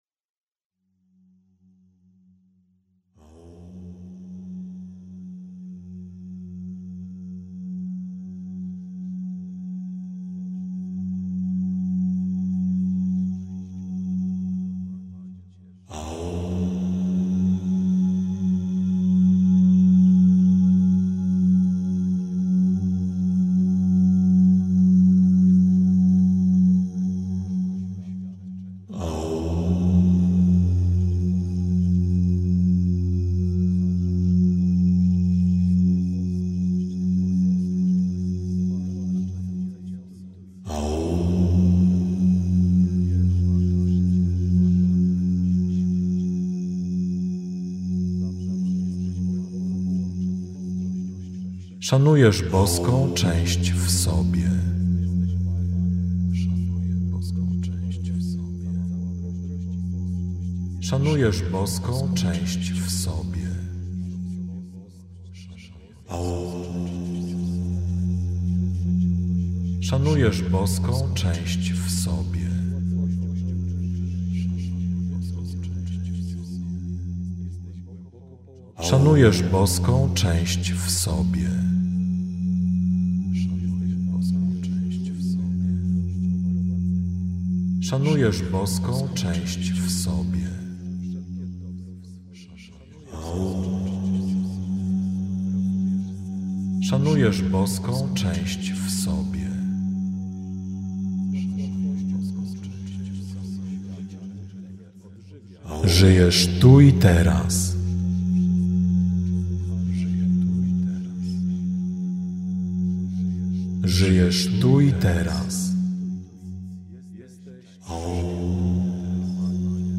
Afirmacje mp3 czakry korony
Czas trwania: 45 minut (dwie wersje: dla kobiety i mężczyzny) + bonus: 12-sto minutowe nagranie afirmacji Jakość: Hi-Fi 320 kbps Rozmiar: 108 MB Zawiera lektora: Tak Zalecane słuchawki: tak